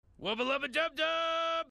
Efeitos Sonoros